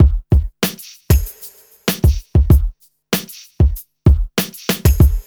4RB96BEAT1-R.wav